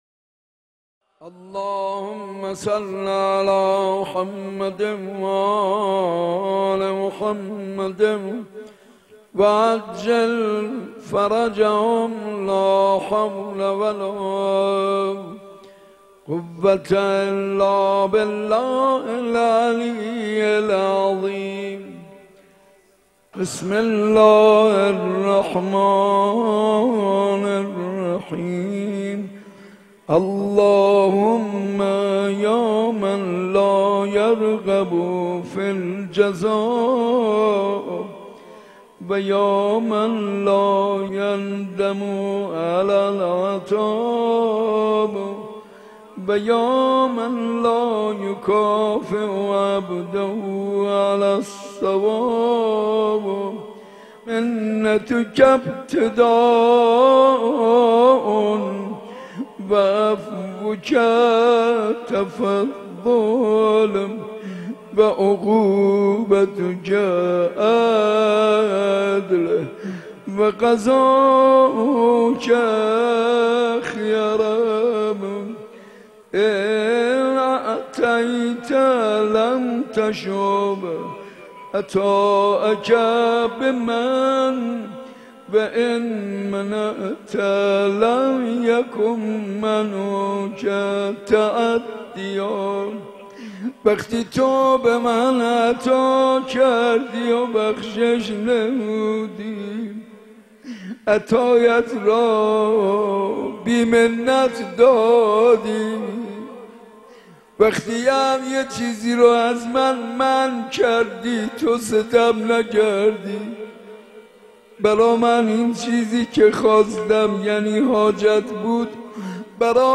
شب بیست و نهم رمضان94 /مسجد ارک